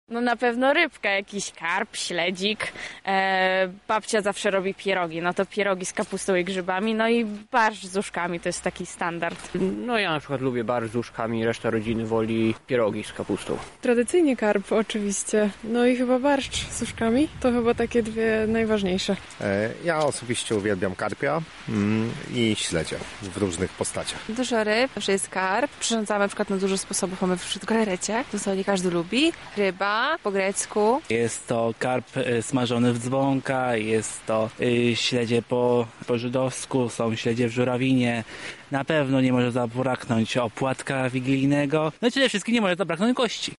O to jakie potrawy są najlepsze w trakcie wigilii i czego nie może zabraknąć przy stole zapytaliśmy mieszkańców
SONDA